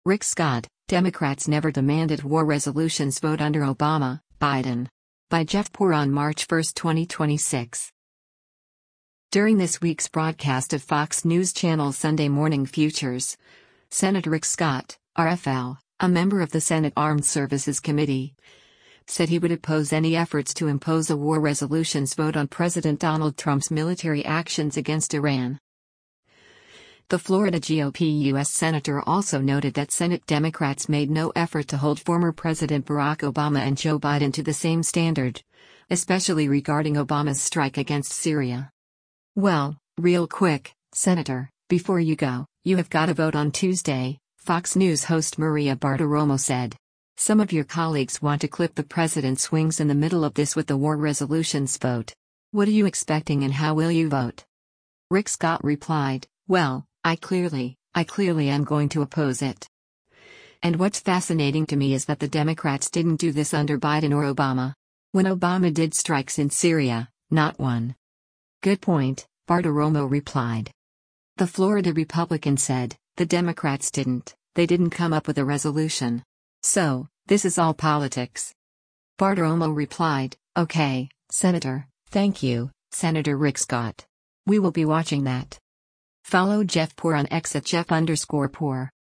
During this week’s broadcast of Fox News Channel’s “Sunday Morning Futures,” Sen. Rick Scott (R-FL), a member of the Senate Armed Services Committee, said he would oppose any efforts to impose a war resolutions vote on President Donald Trump’s military actions against Iran.